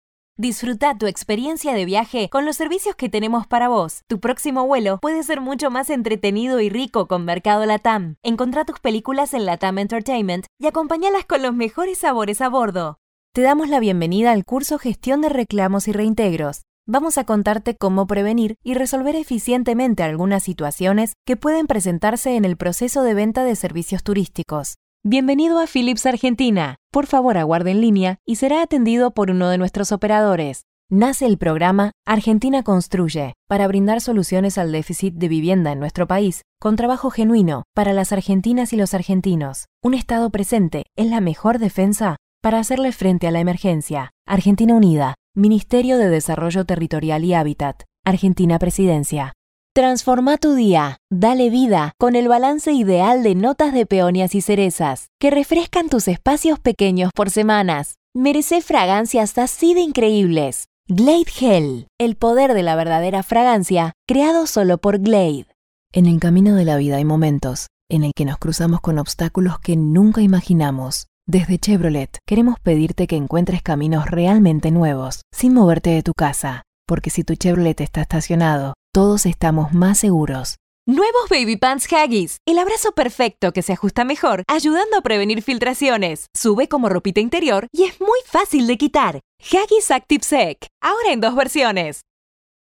Spanisch (Argentinisch)
Präzise
Artikulieren